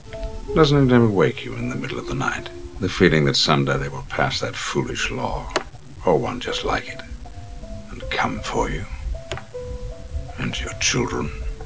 X-Men Movie Sound Bites